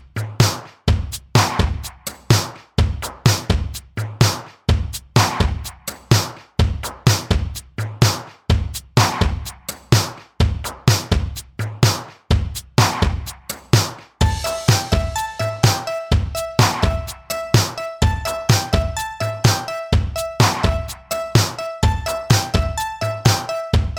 Minus Lead Guitar Pop (1980s) 4:04 Buy £1.50